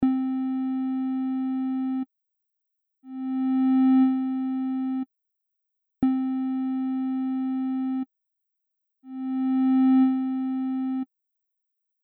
ADSR-Hüllkurve mit A kurz und A lang / DSR konstant (Operator-Einstellungen: A 0,00ms & 1s - D 600ms - R 50ms - S -10db)
ADSR_-_kurz_A_lang_A.mp3